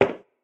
inside-step-3.ogg.mp3